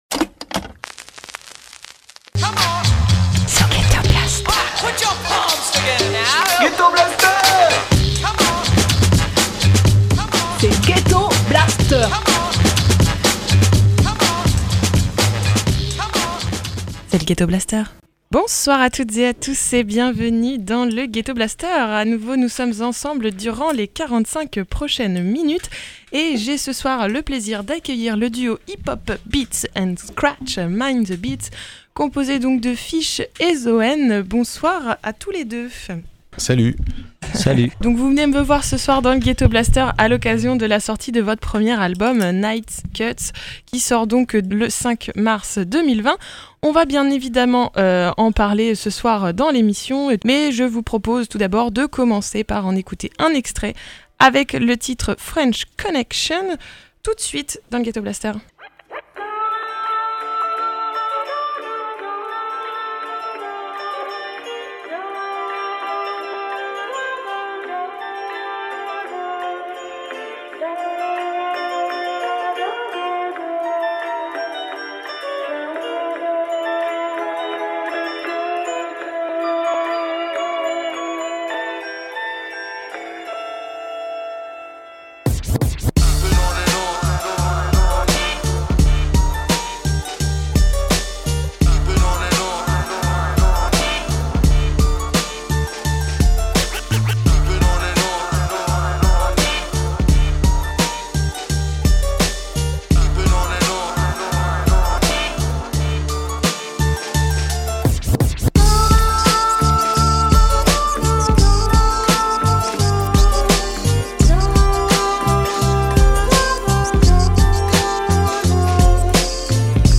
Durant les 45 minutes de l’émission nous avons donc parlé musique, production, scratch, influences et live – puis nous avons écouté quelques titres de l’album bien évidemment !